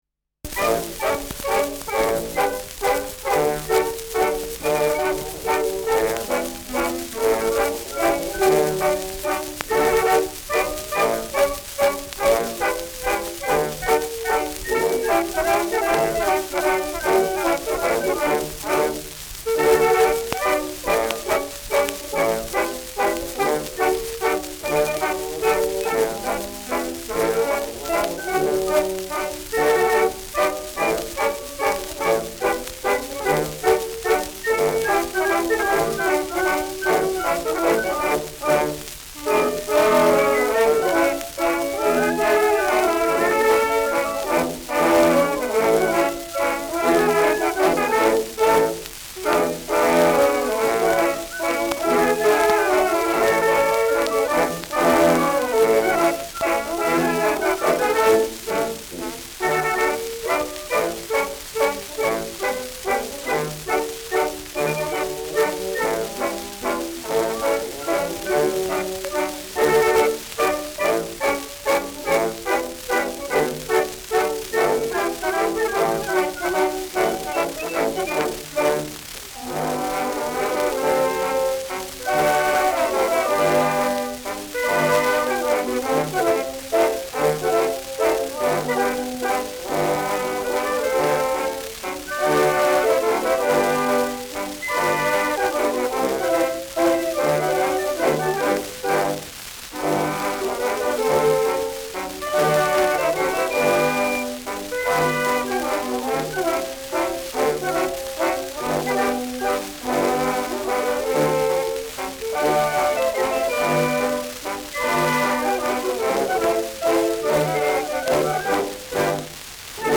Schellackplatte
Tonrille: Abrieb : graue Rillen : leichte Kratzer durchgängig
wegen „Hängens“ fehlender Anfang
Stadtkapelle Fürth (Interpretation)